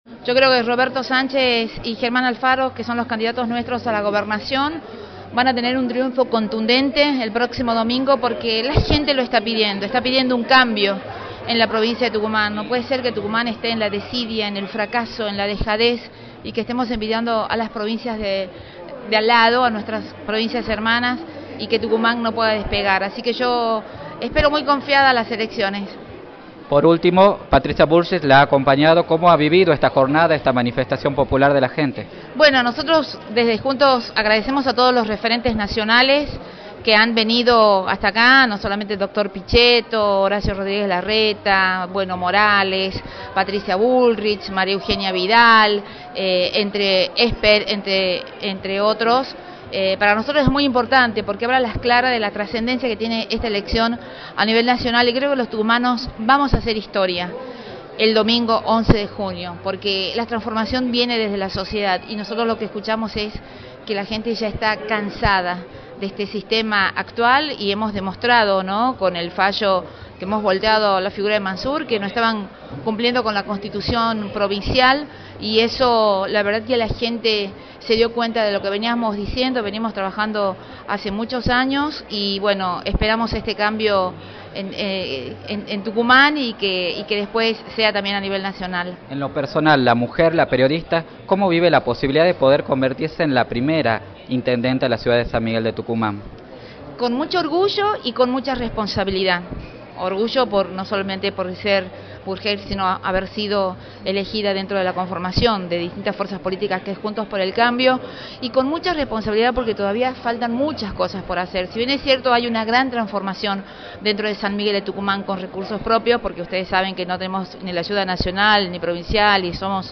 entrevista emitida por Radio del Plata Tucumán